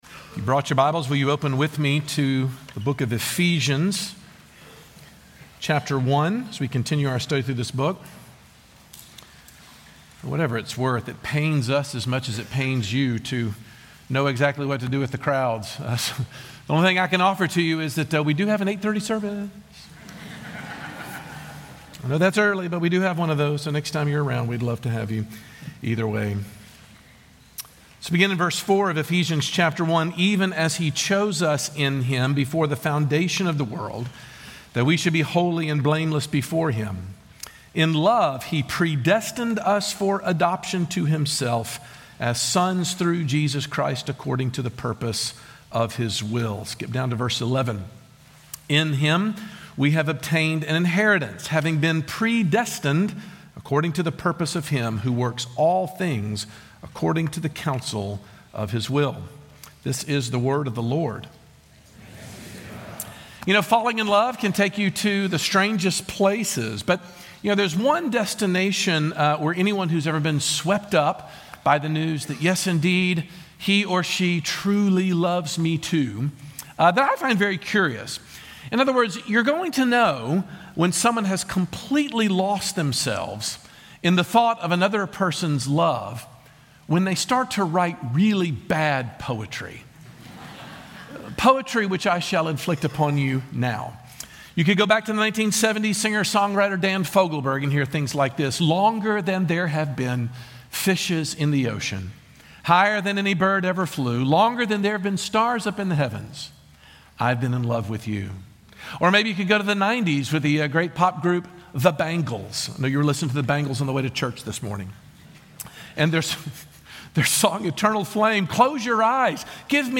Paul is excited to say that a huge blessing of being "in Christ" is the knowledge that it was true before we were ever born. Sermon Points: